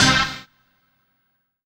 HOUSE115.wav